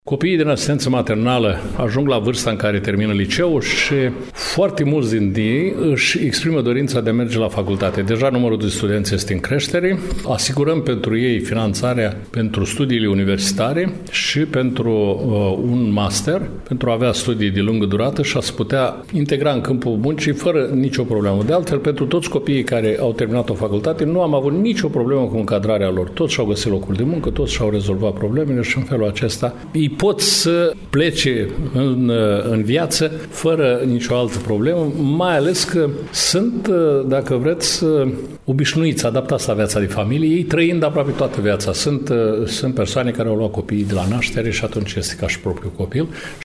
Directorul Direcției Generale de Asistență Socială și Protecția Copilului Vaslui, Ionel Armeanu Stefanică, a declarat că numărul copiilor instituționalizați care termină liceul va crește în următorii ani și, astfel, numărul cererilor pentru a urma studiile superioare va fi mai mare.